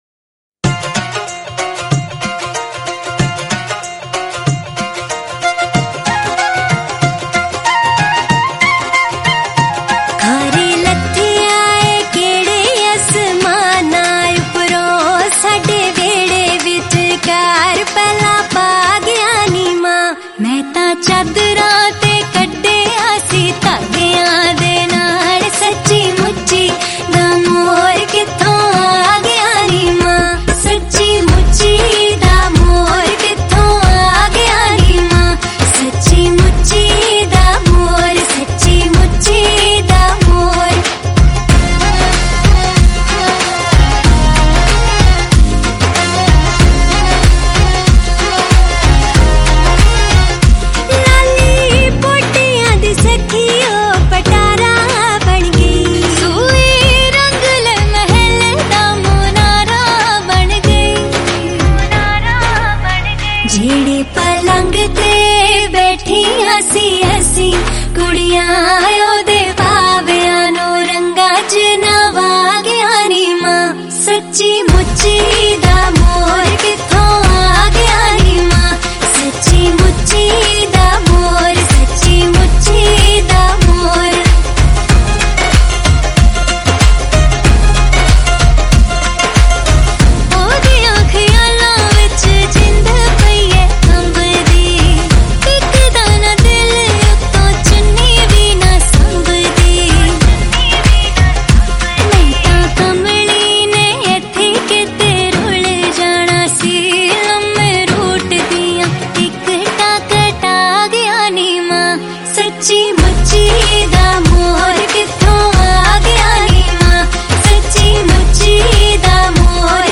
Releted Files Of Latest Punjabi Song